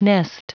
Prononciation du mot nest en anglais (fichier audio)